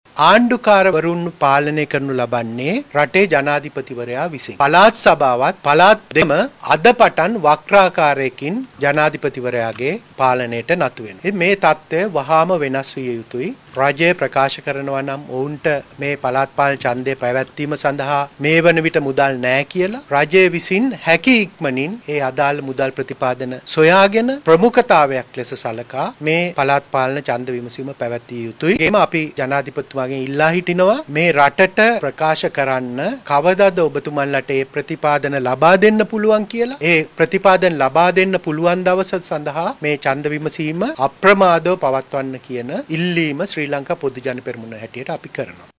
සාගර කාරියවසම් මහතා මේ බව පැවසුවේ අද කොළඹ පැවති මාධ්‍ය හමුවකදී.